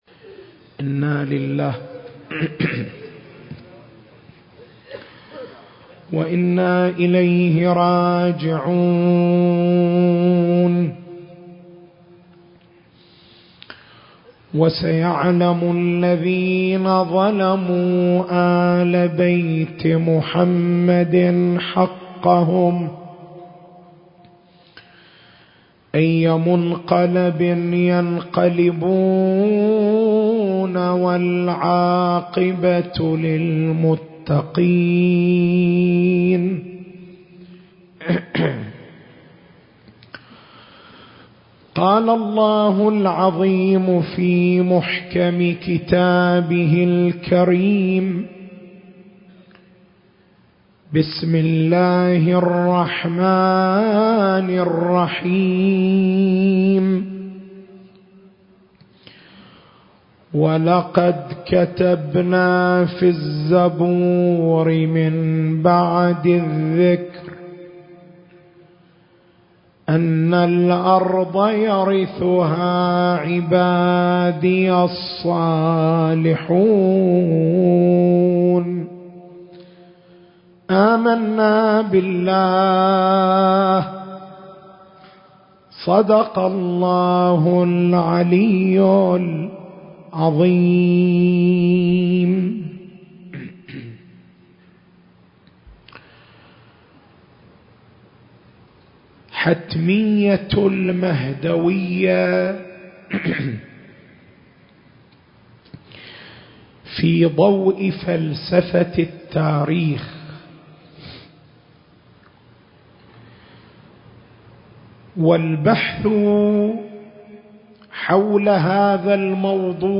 المكان: مأتم بن جمعة- القطيف التاريخ: 2019